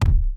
Heavy_Step01.wav